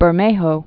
(bər-māhō, bĕr-)